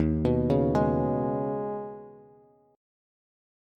Eb7sus4#5 Chord
Listen to Eb7sus4#5 strummed